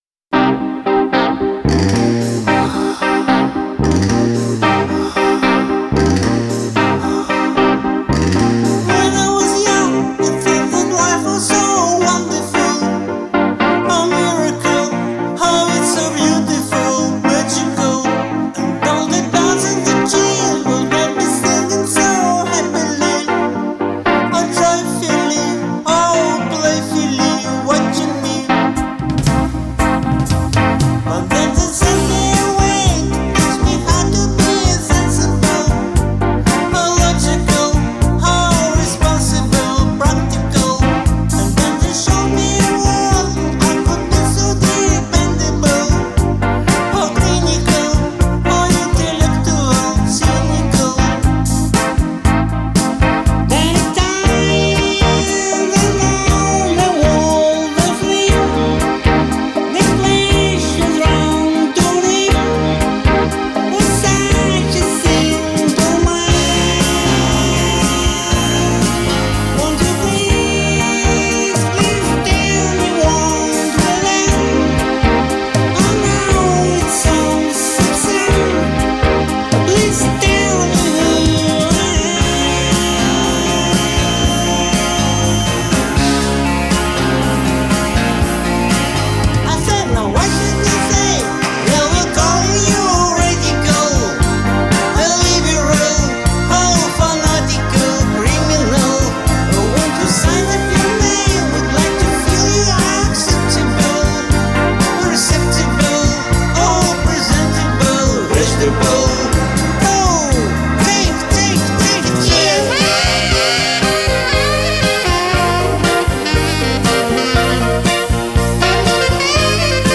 Голос как бы придавлен.